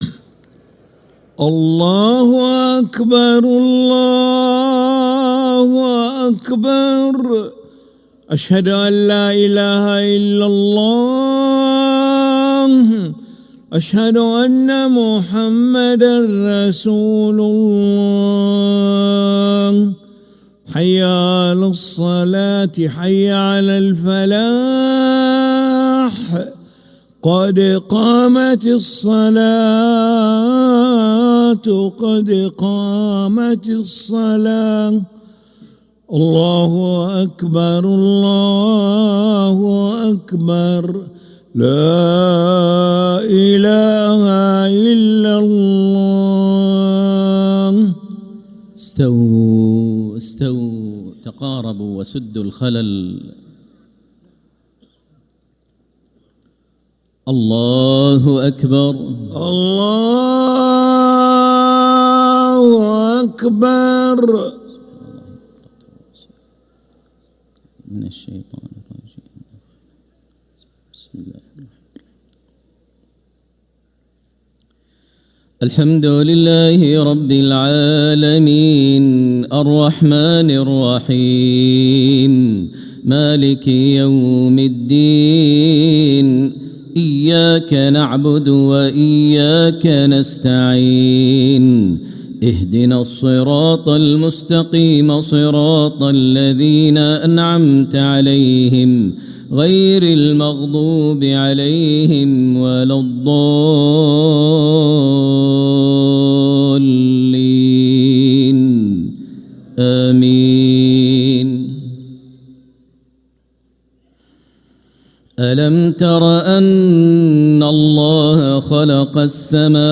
Makkah Isha - 05th April 2026